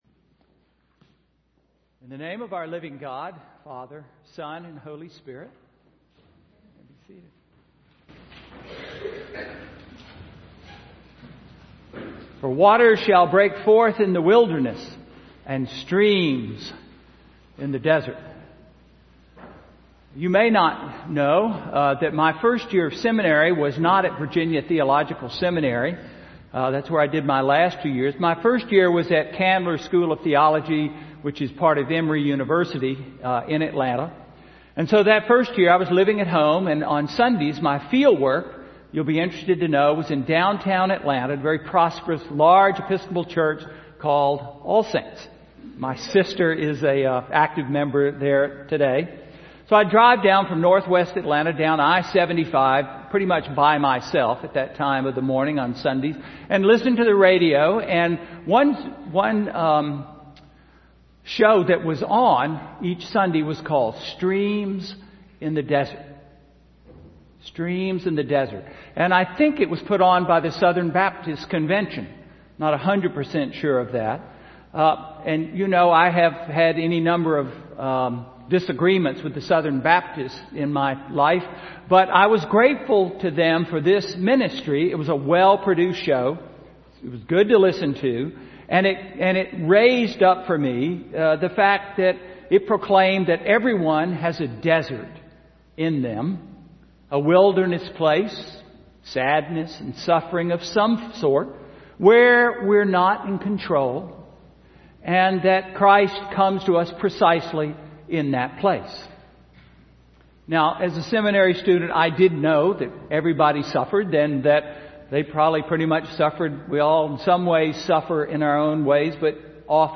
Sermon–December 15, 2013